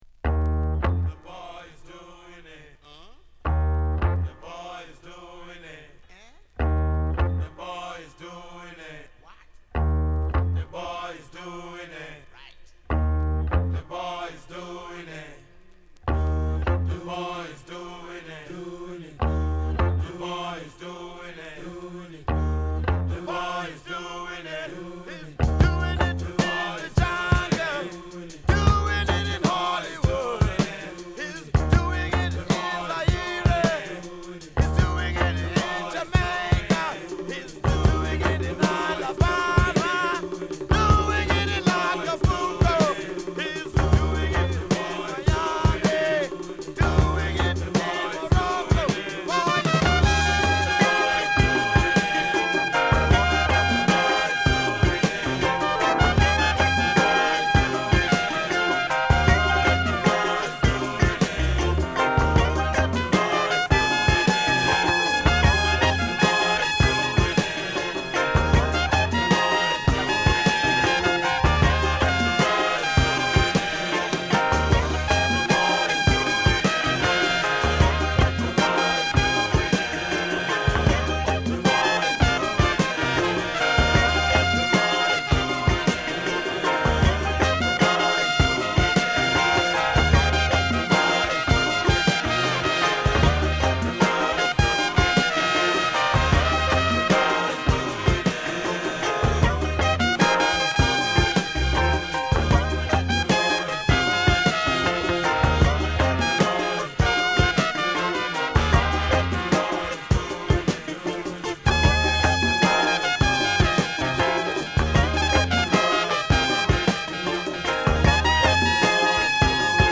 録音はナイジェリアのラゴス。